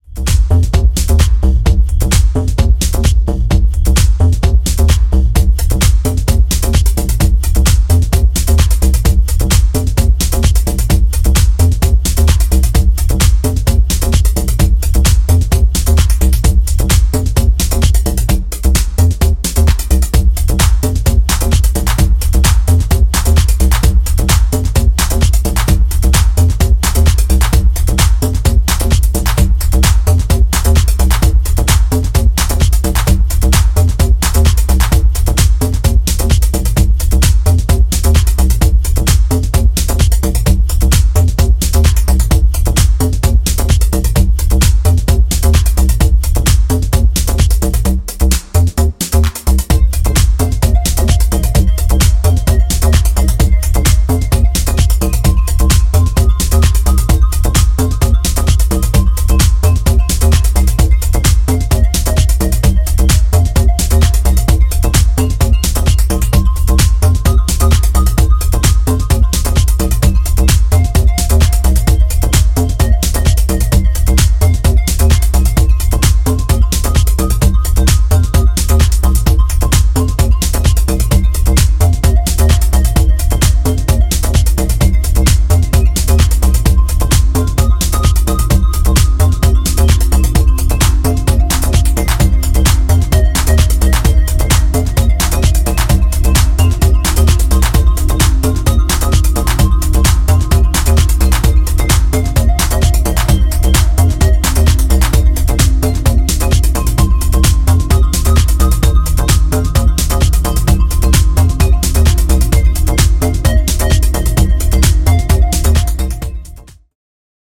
ダビーなシンセの飛ばしに心掴まれるパンピンなアシッド・ハウス
角が取れつつ、しっかりとフロアを主導するビートが心地良い3トラックス、流石でございます！